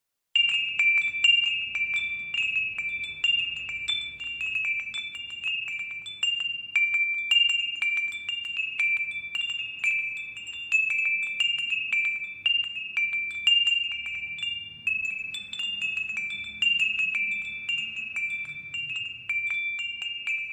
Mandala chime Chakra 1 Root chakra (Muladhara) | | Yoga accessories and meditation needs